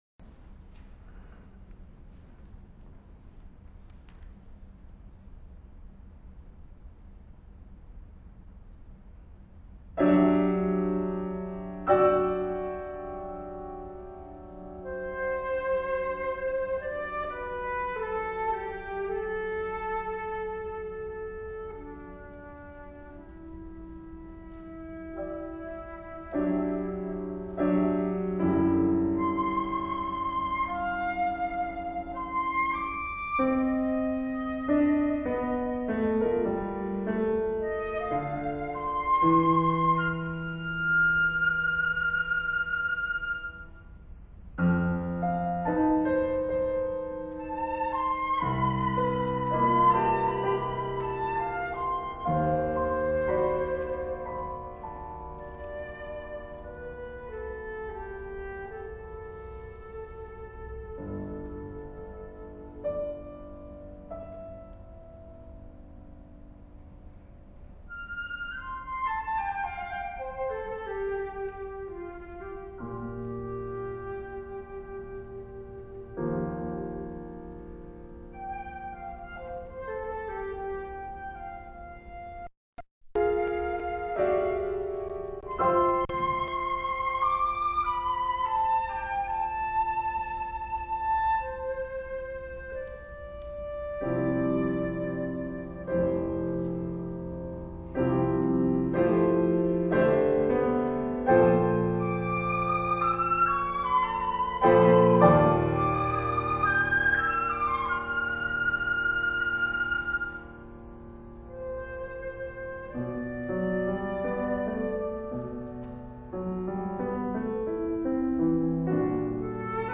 Flute & piano (10′)